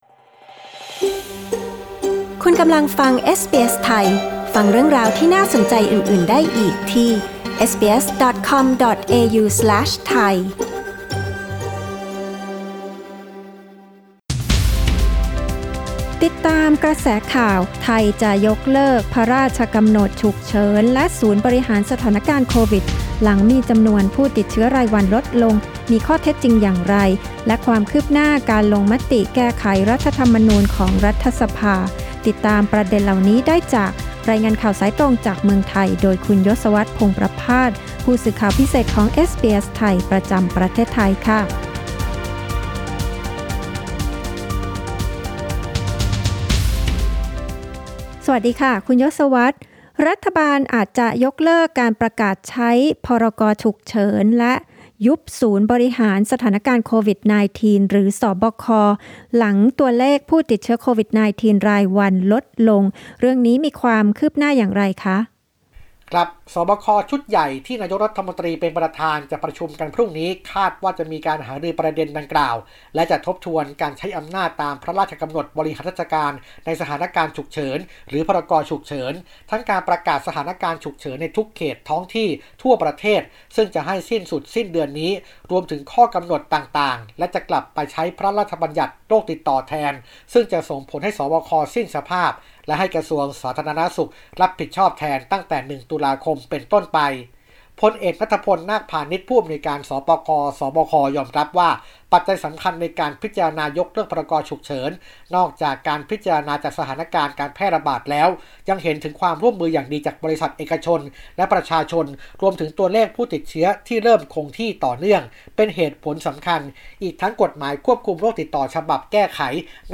รายงานข่าวสายตรงจากเมืองไทย จากเอสบีเอส ไทย Source: Pixabay